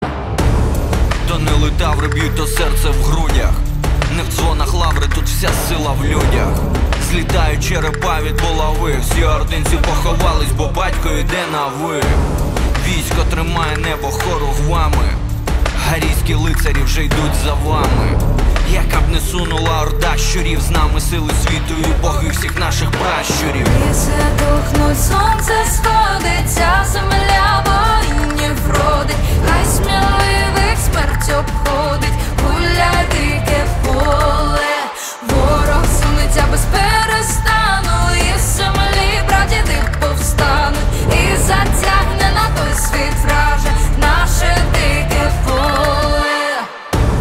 • Качество: 320, Stereo
мужской голос
спокойные
красивый женский голос
Украинский рэп